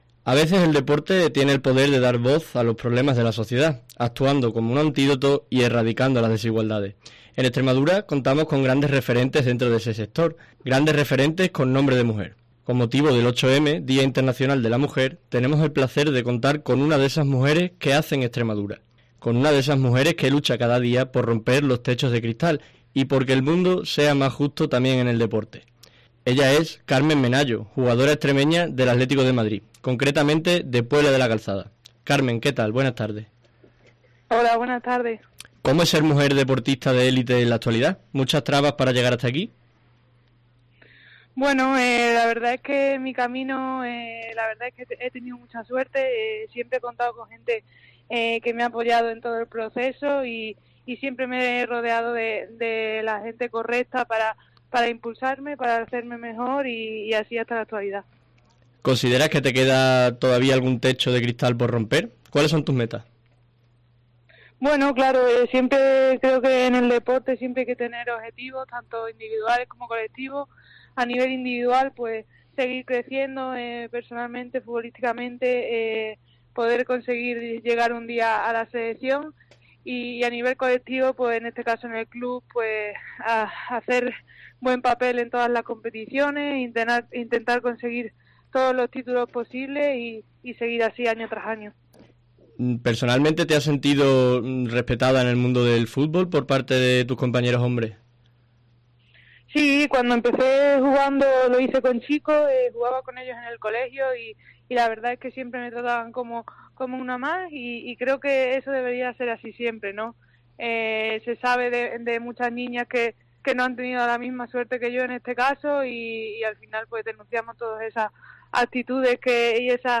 En una entrevista exclusiva con COPE Extremadura, Carmen comparte su perspectiva y experiencias, ofreciendo una visión inspiradora de lo que significa ser una mujer en el deporte de élite.